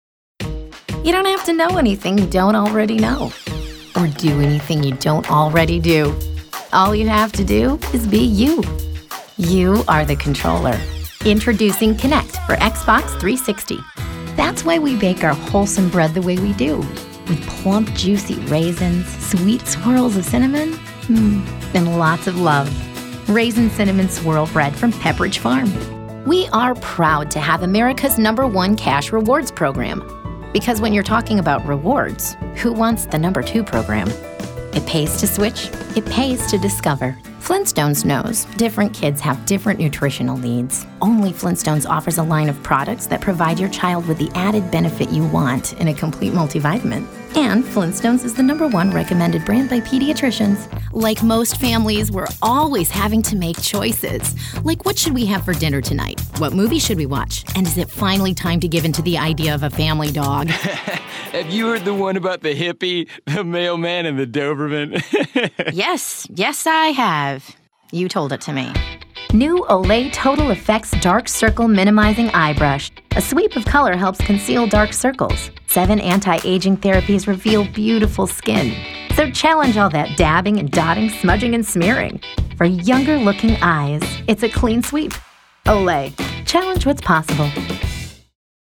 Voiceover : Commercial : Women